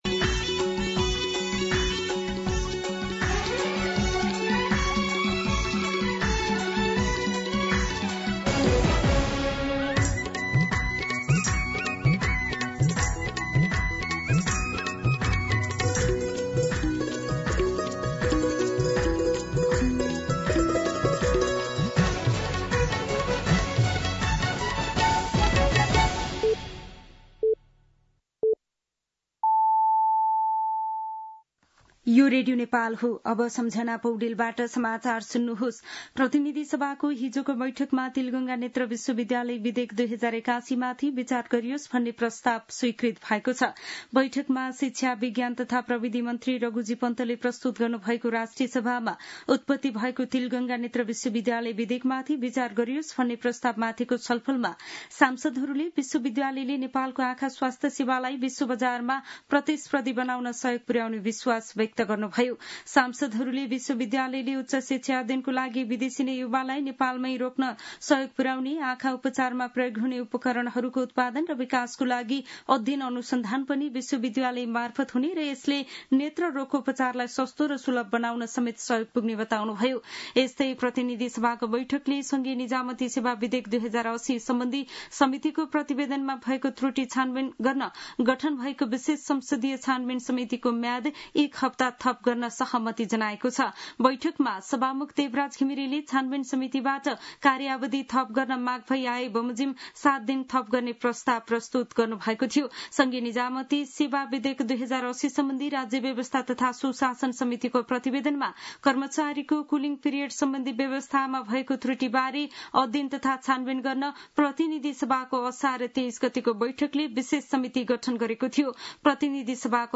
दिउँसो १ बजेको नेपाली समाचार : १० साउन , २०८२
1-pm-Nepali-News-2.mp3